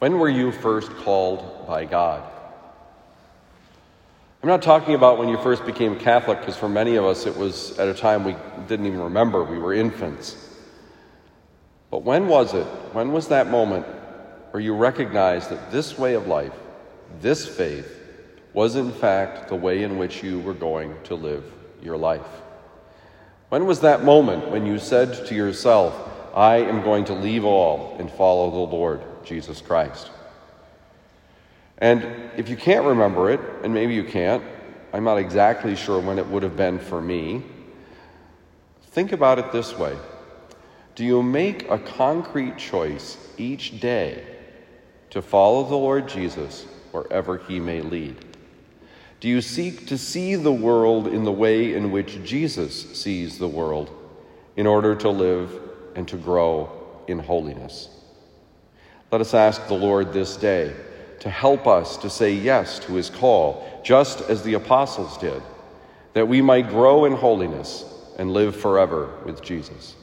Homily given at Christian Brothers College High School, Town and Country, Missouri.